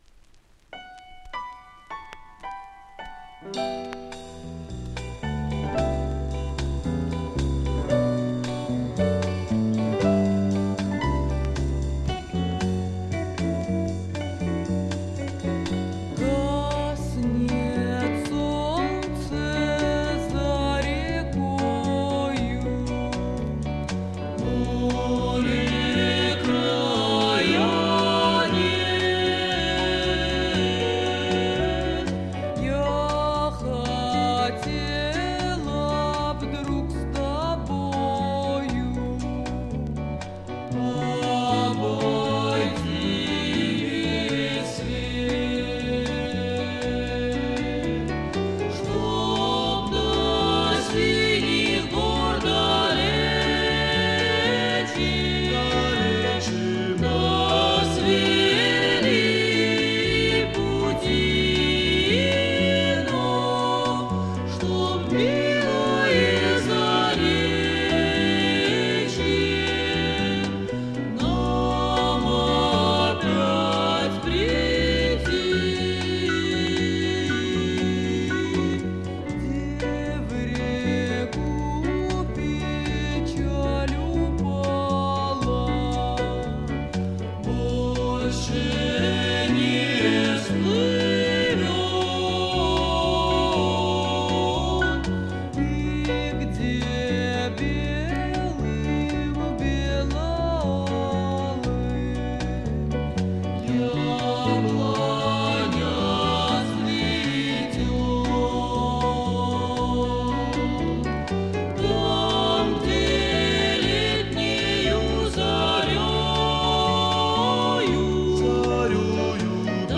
С какой то пластинки (70-е).
по лёгкому акценту и манере пения